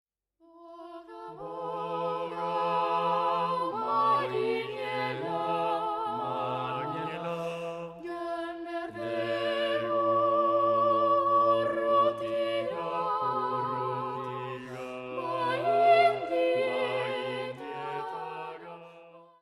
Cuarteto mixto